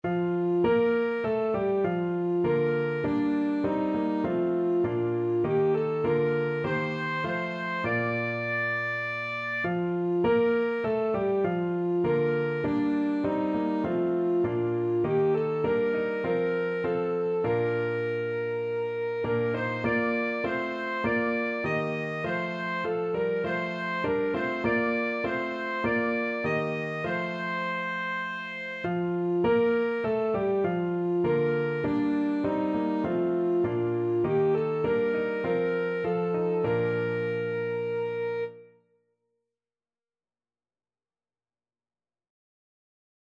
Alto Saxophone
Classical (View more Classical Saxophone Music)